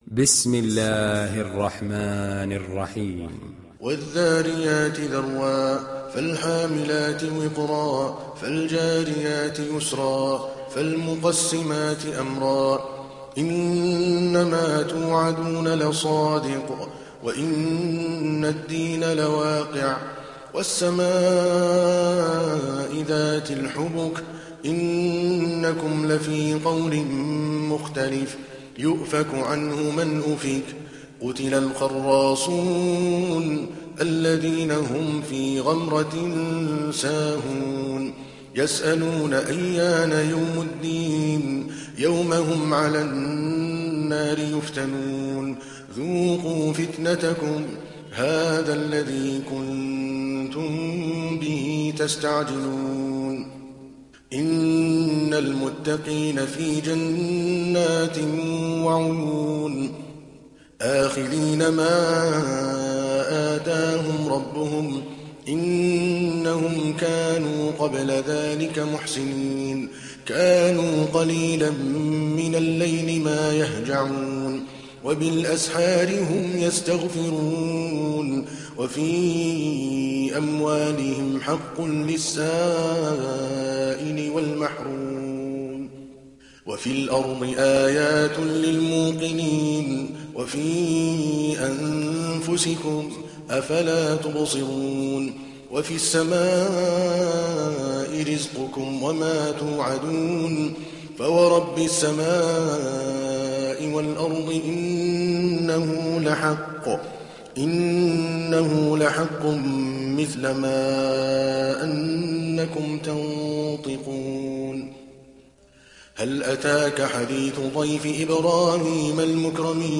تحميل سورة الذاريات mp3 بصوت عادل الكلباني برواية حفص عن عاصم, تحميل استماع القرآن الكريم على الجوال mp3 كاملا بروابط مباشرة وسريعة